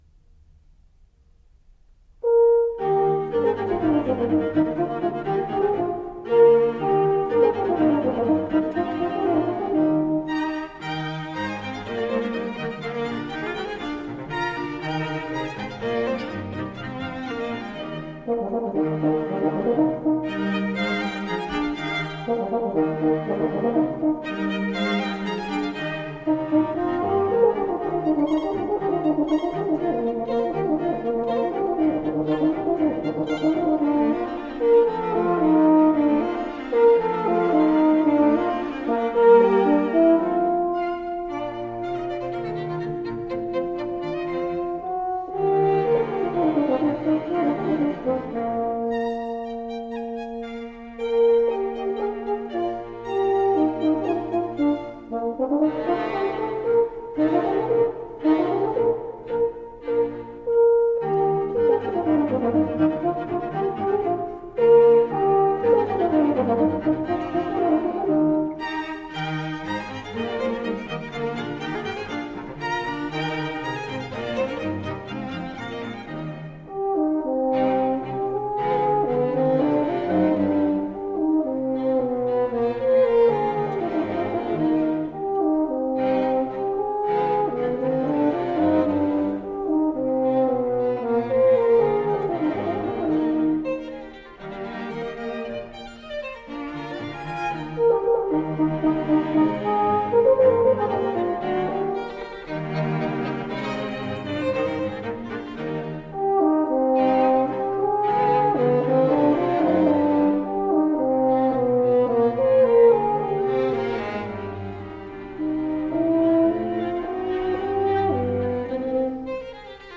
Allegro - Horn Quintet